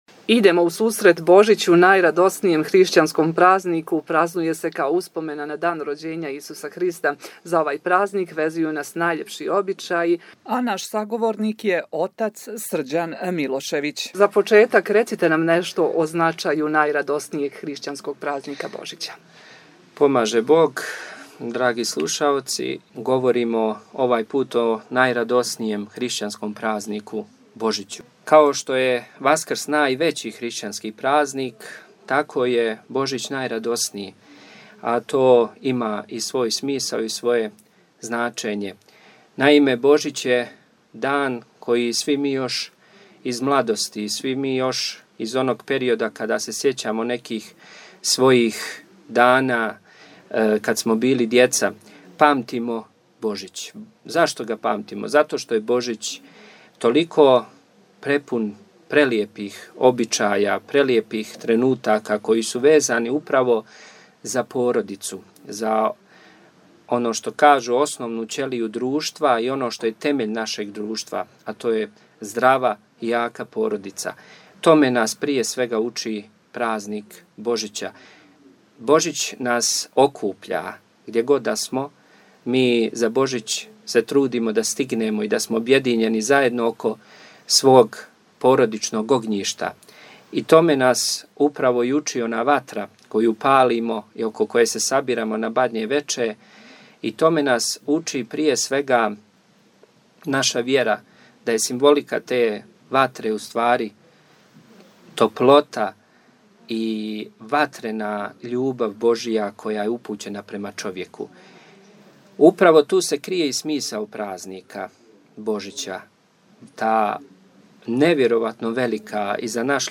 Пред најрадоснији хришћански празник Божић подсјетили смо се разговора